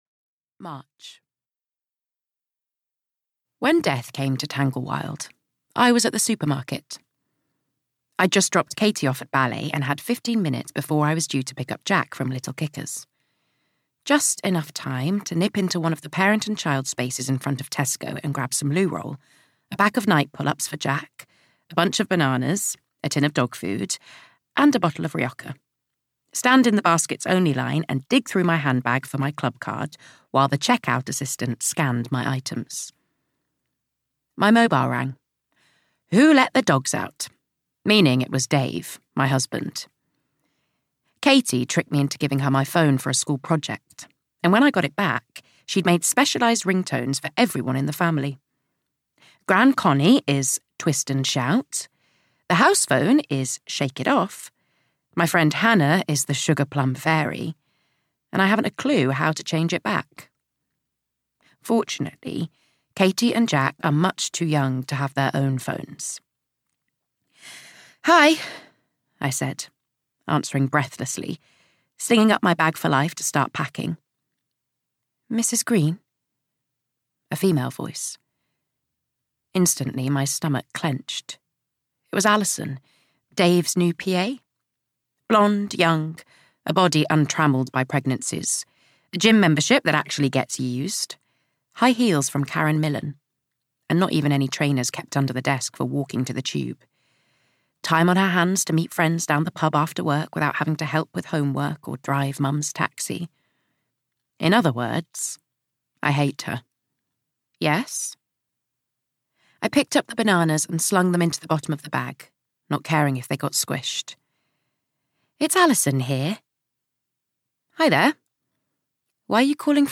Audio knihaThe House of Love and Dreams (EN)
Ukázka z knihy